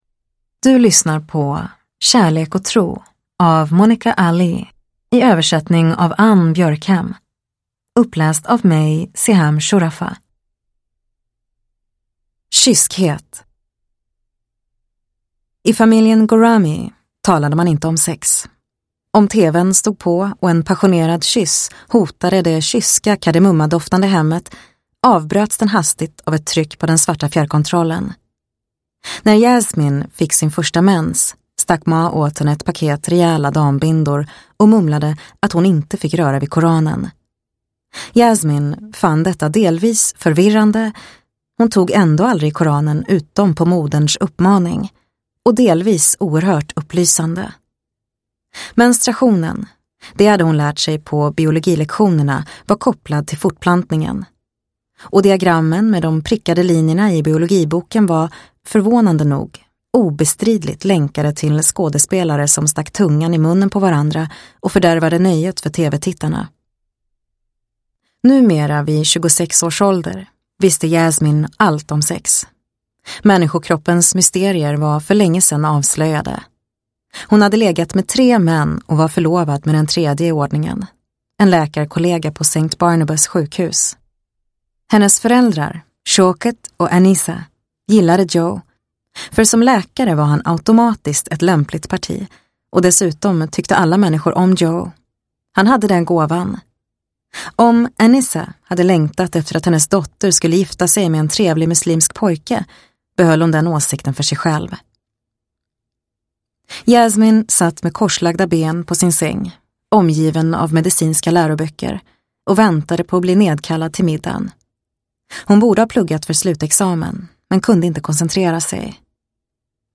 Kärlek och tro – Ljudbok – Laddas ner